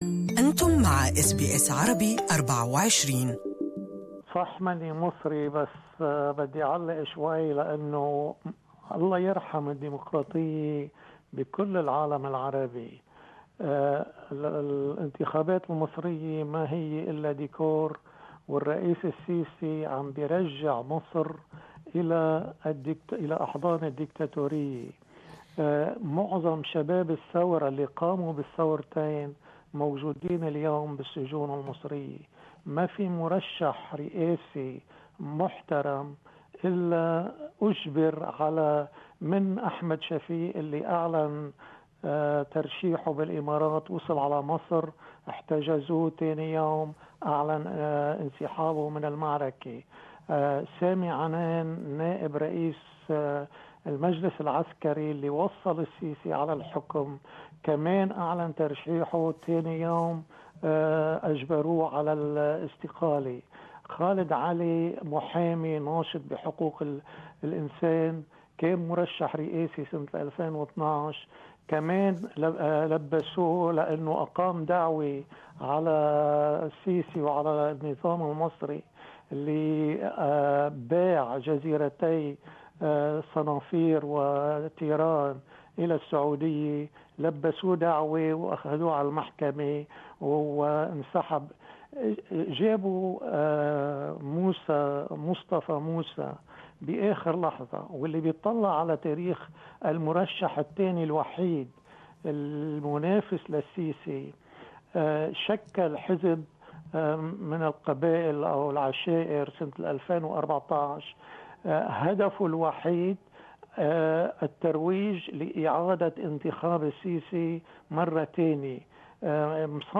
GMA listeners participated in the talkback this morning to express their views on the upcoming presidential elections.